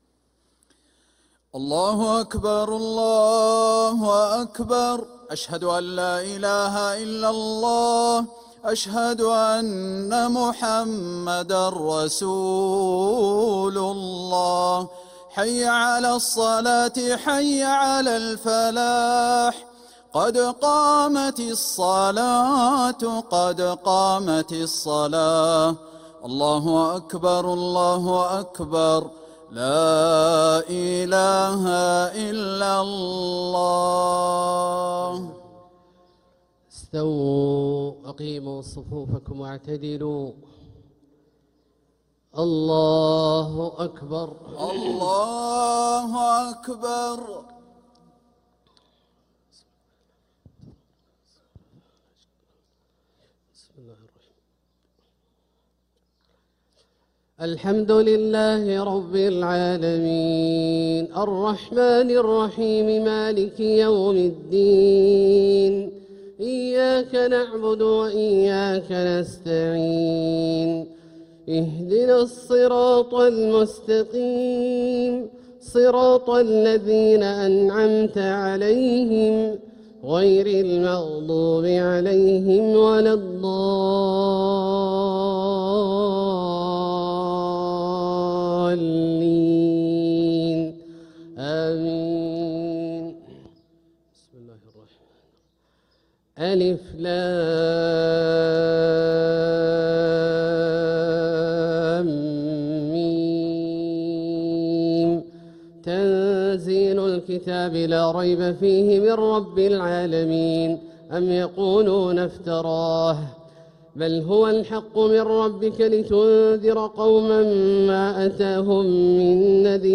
Haramain Salaah Recordings: Makkah Fajr - 27th March 2026